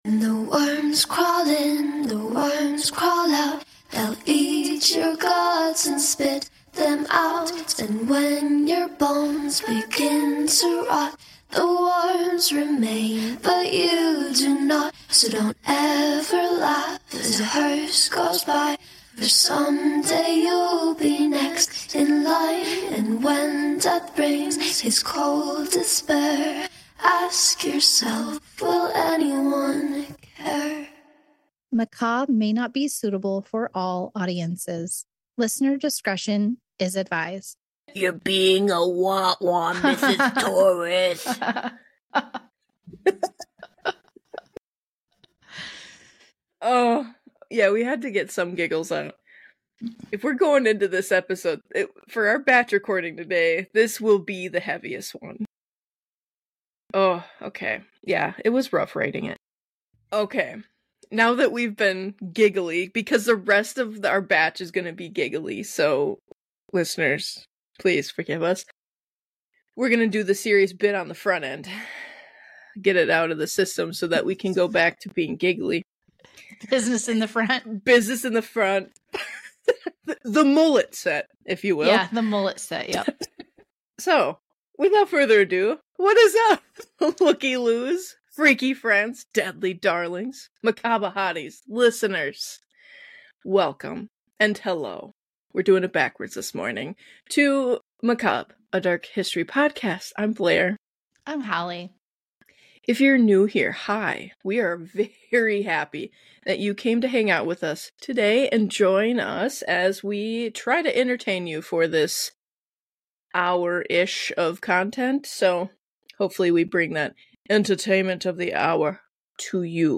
Join the ladies as they talk about a shameful subject in America’s history.